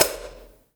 Cymbol Shard 14.wav